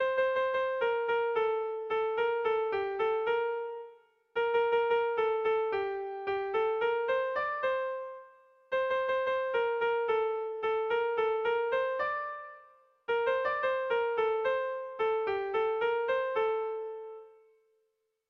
Irrizkoa
Zortziko txikia (hg) / Lau puntuko txikia (ip)
ABA2D